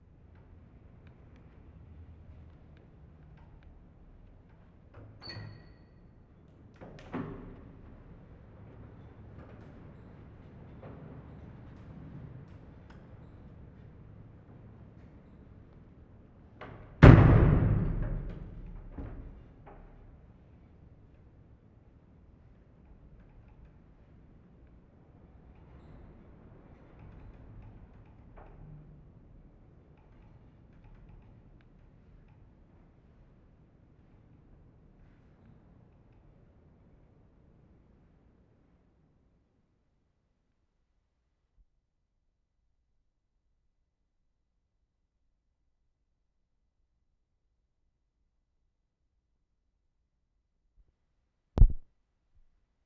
Wensleydale, Yorkshire May 7/75
DOOR TO ST. OSWALD'S CHURCH
mark * door squeaks open and bangs shut (very loud) [0:04]